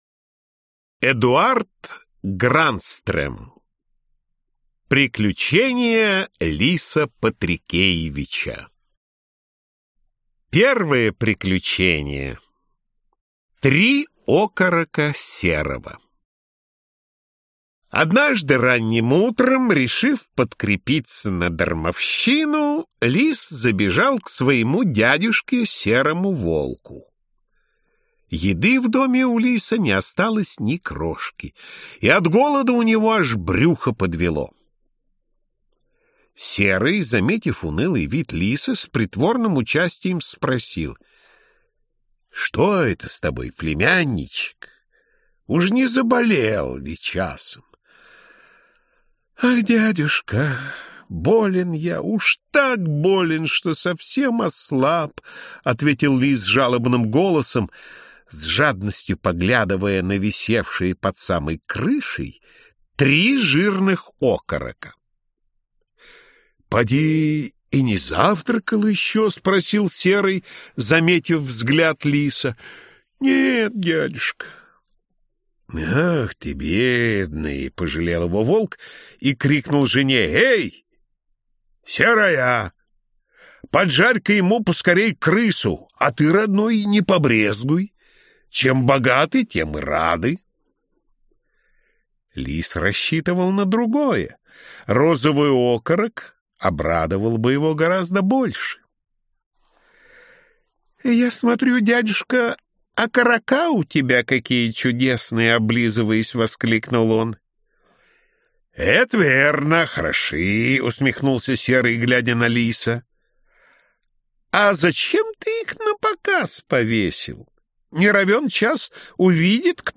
Аудиокнига Приключения Лиса Патрикеевича | Библиотека аудиокниг